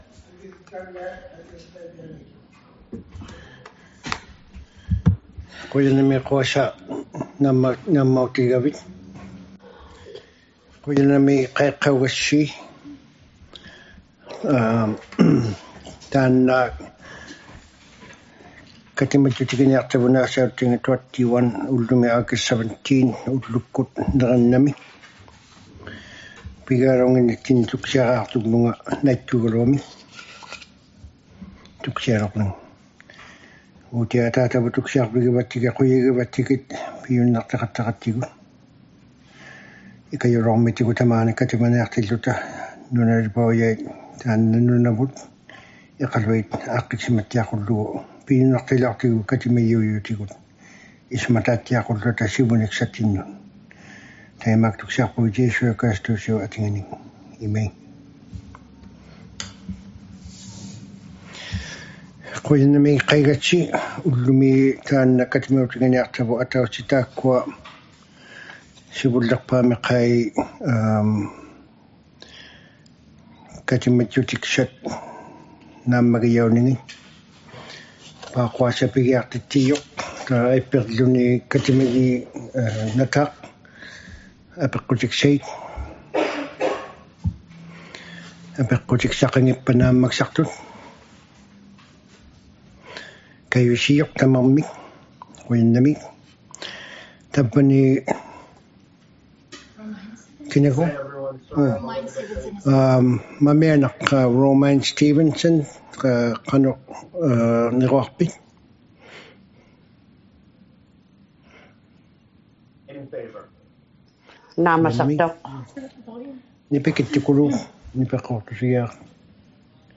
ᑐᐊᕕᕐᓇᑐᒃᑯᑦ ᑲᑎᒪᓂᖓᑦ ᑲᑎᒪᓖᑦ # 21 - Special City Council Meeting # 21 | City of Iqaluit
special_city_council_meeting_21_august_17_2022_inuk.mp3